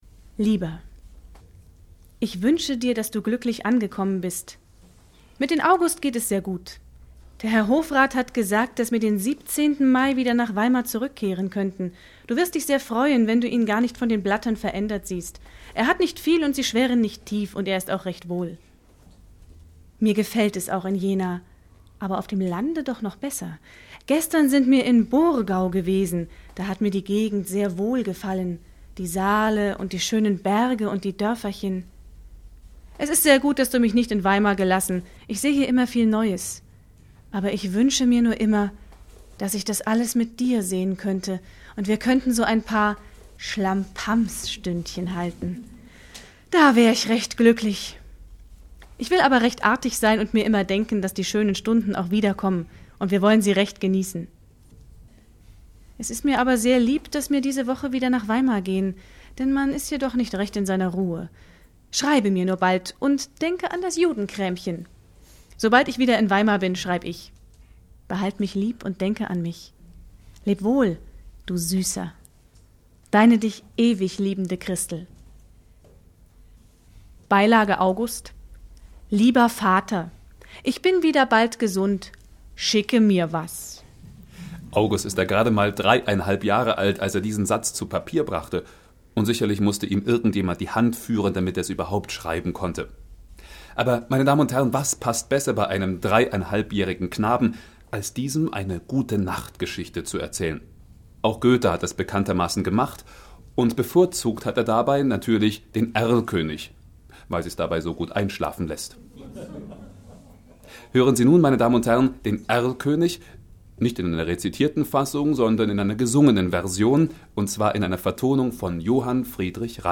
Ein literarisch-musikalisches Programm
Bearbeiteter Live-Mitschnitt